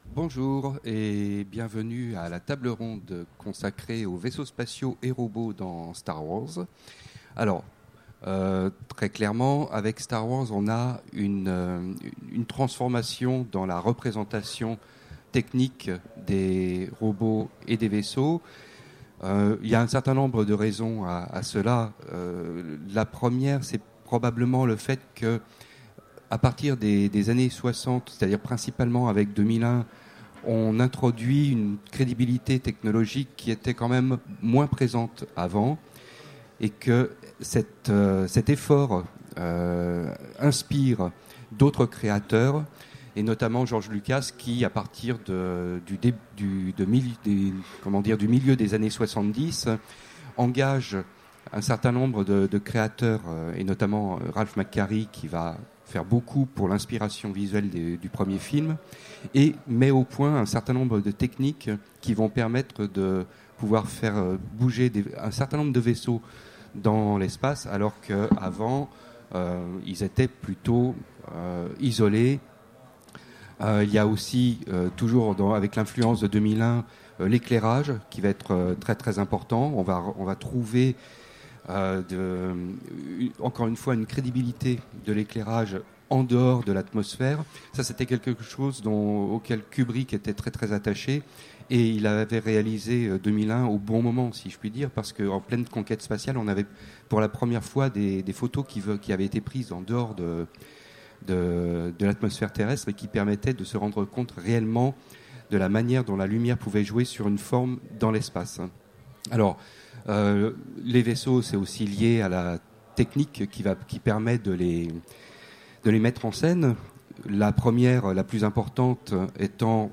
Utopiales 2016 : Conférence Vaisseaux spatiaux et robots dans Star Wars